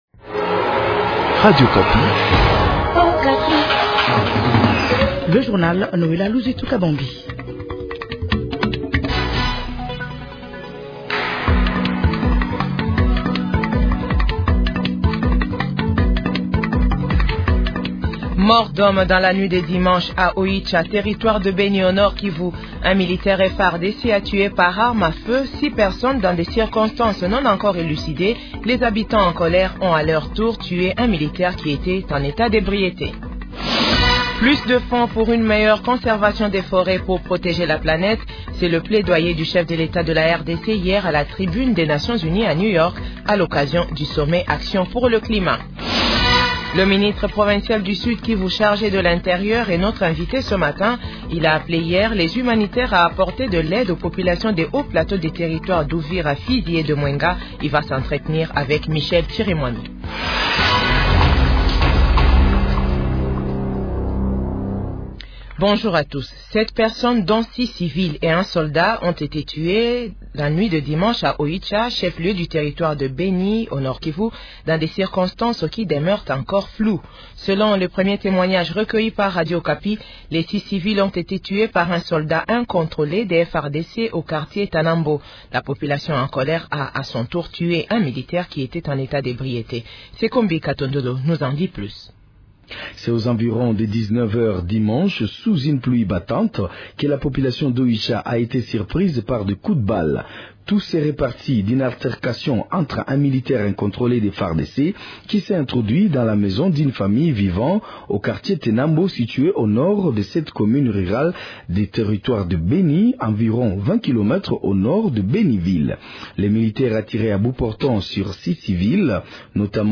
Le ministre provincial de l’Intérieur, Lwabandji Lwasi Ngabo, a fait cet appel lundi 23 septembre au cours d’une réunion tenue au bureau de la MONUSCO à Bukavu.